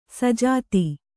♪ sajāti